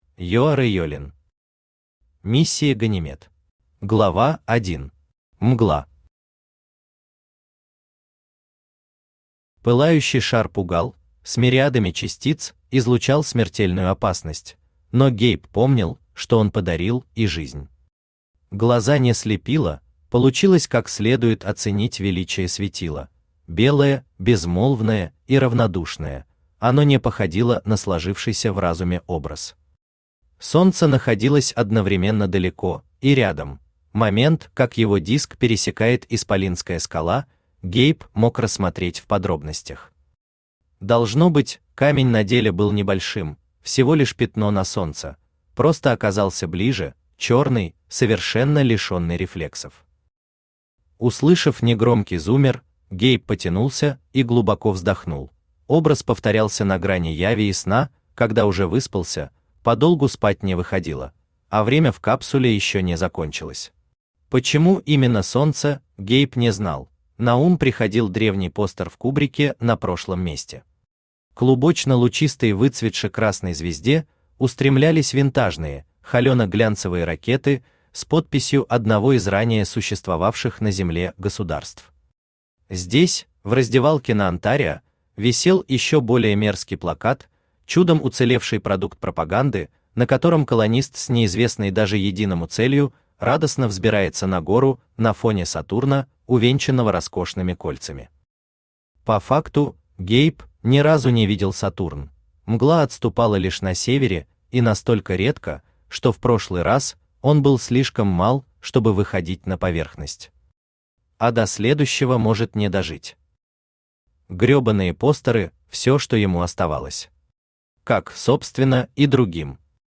Aудиокнига Миссия «Ганимед» Автор Женя Ео Читает аудиокнигу Авточтец ЛитРес.